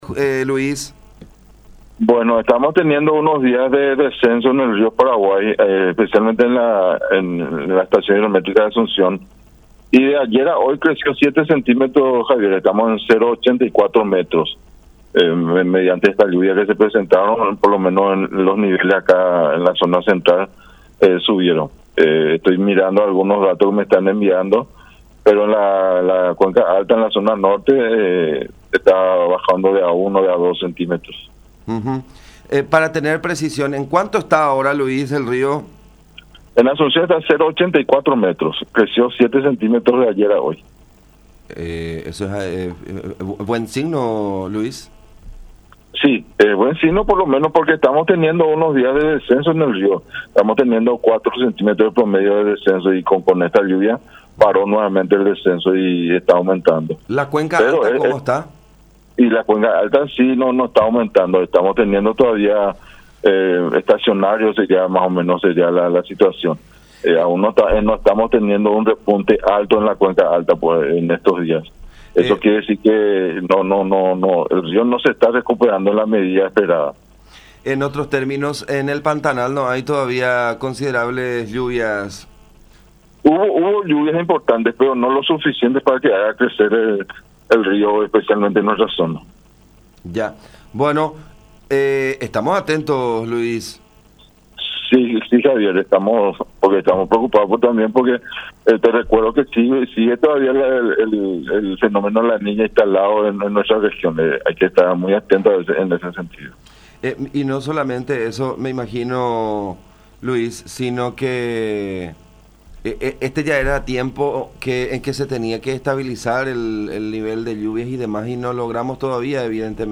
en conversación con La Unión, haciendo alusión a la situación en la que se halla el cauce en zona del Puerto de Asunción.